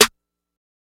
Mad High Snare.wav